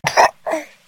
babyburps.ogg